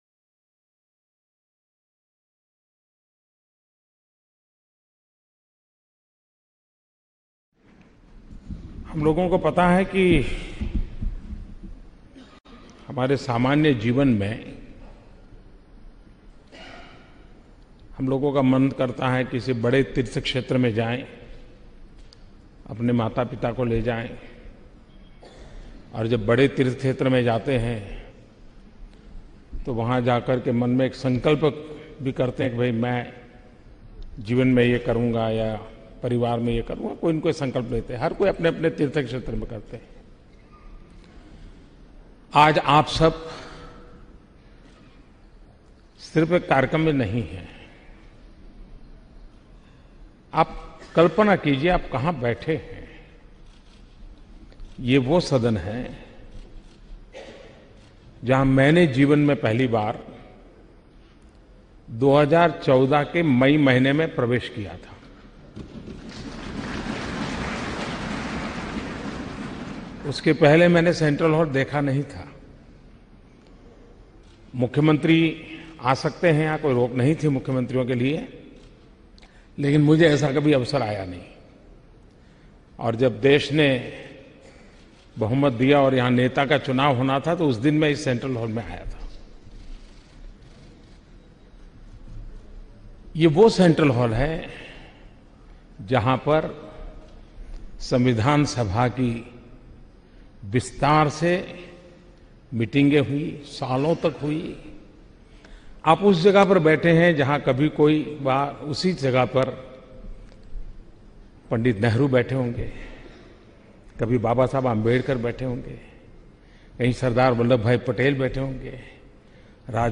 News Report / जनता की भलाई के लिए मोदी ने माँगा विपक्ष का समर्थन